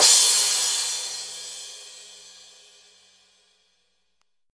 TM-88 Crash #07.wav